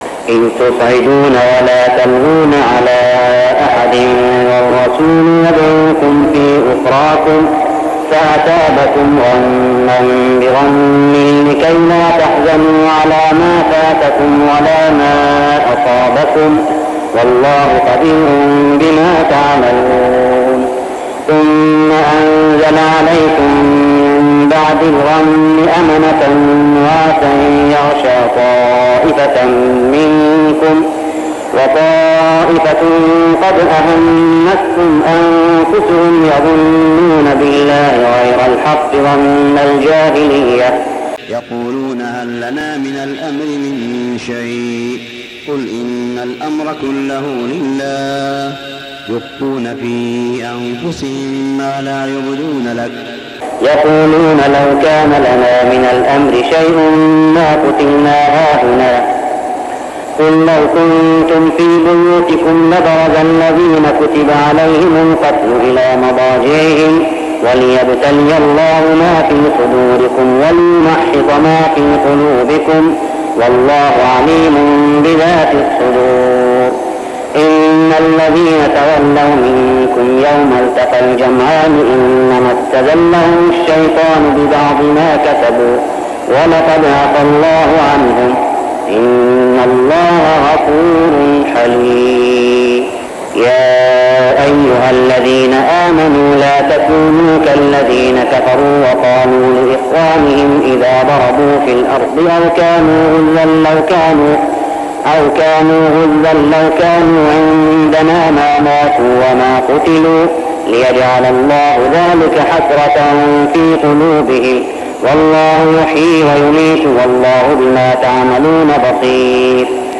من ليالي رمضان 1405هـ سورة آل عمران 153-180 | From nights of Ramadan Surah Al Imran > تراويح الحرم المكي عام 1405 🕋 > التراويح - تلاوات الحرمين